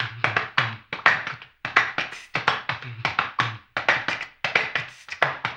HAMBONE 05-R.wav